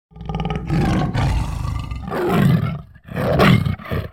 tiger-sound